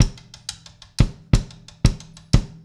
Stickrim loop 93bpm.wav